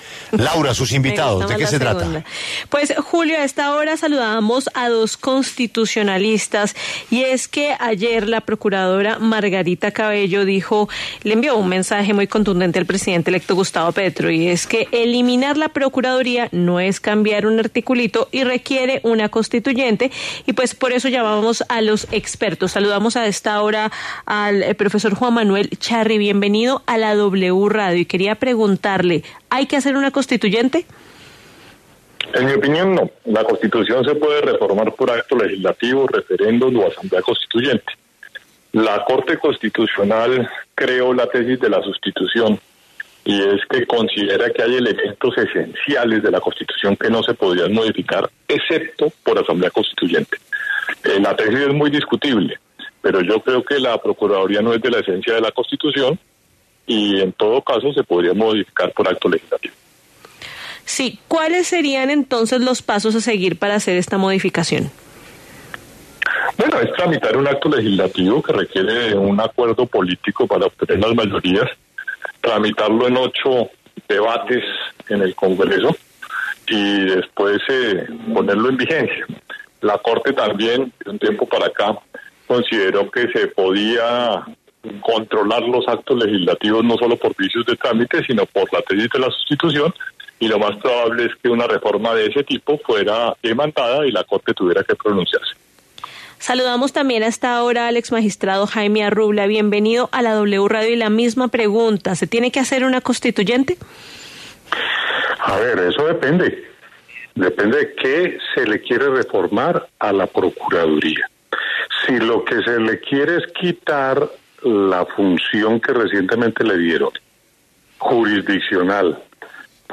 La W conversó con dos expertos constitucionalistas acerca del mensaje de la procuradora Margarita Cabello al presidente electo Gustavo Petro sobre la necesidad de una Constituyente para eliminar la Procuraduría General de la Nación.